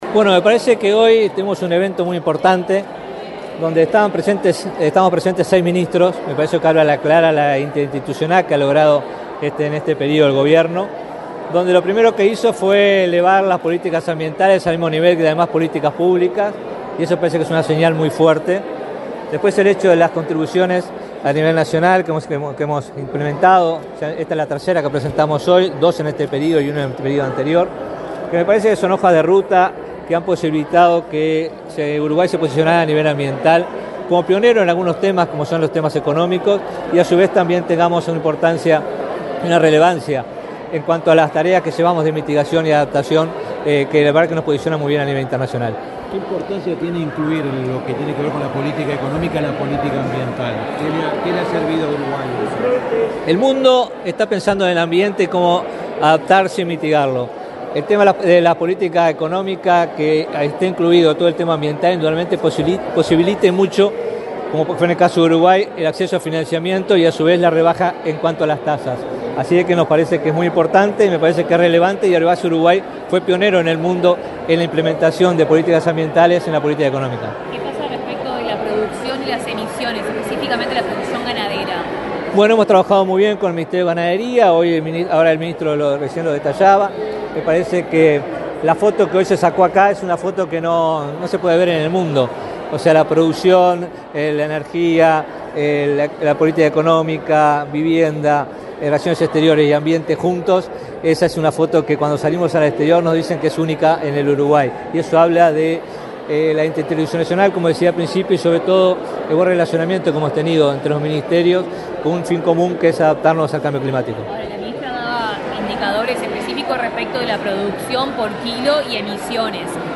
Declaraciones del ministro de Ambiente, Robert Bouvier
Este martes 17 en la Torre Ejecutiva, el ministro de Ambiente, Robert Bouvier, dialogó con la prensa, luego de participar en la presentación de la 3.ª